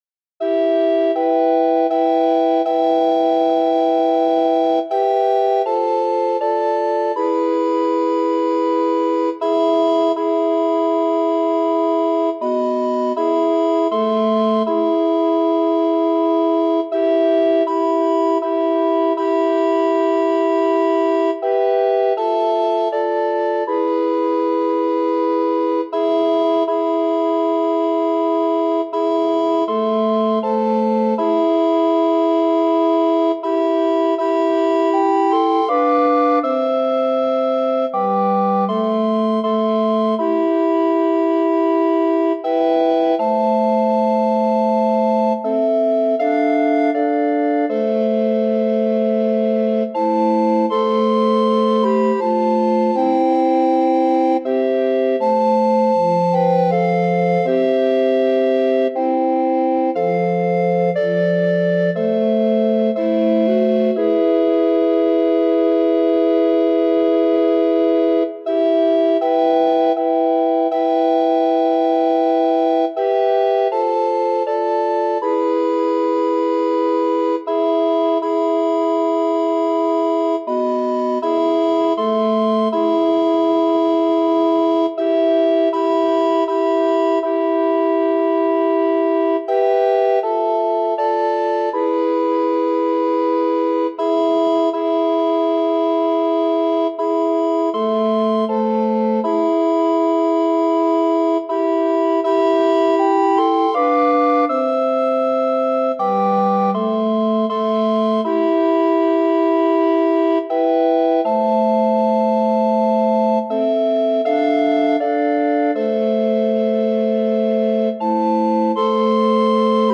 Play Third Mode Melody (for 4 SATB recorders), print the Sheet Music Score, download the printable parts for Soprano, Alto, Tenor or Bass recorders, download the MIDI file or the
Third-Mode-Melody-SATB-recorders.mp3